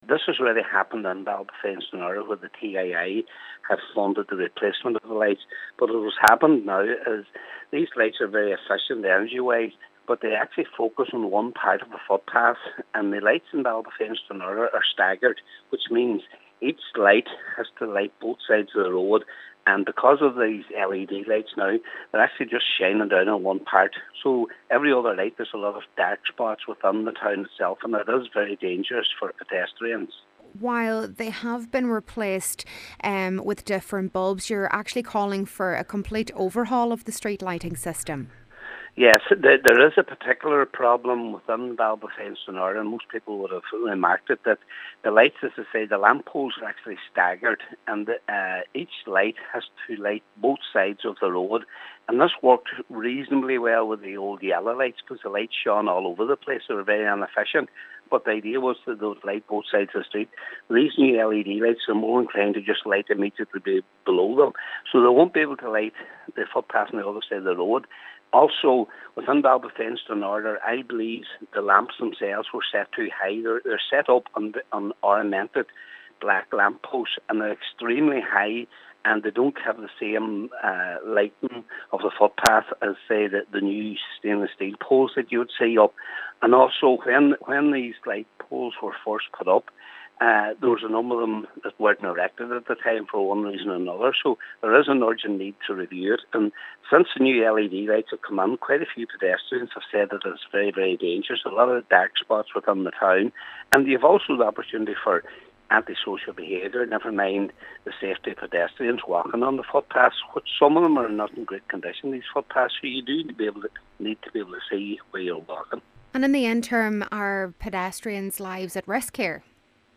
Local Cllr Patrick McGowan says an overhaul of the system would address the situation but is warning that pedestrians lives are at risk if action isn’t taken: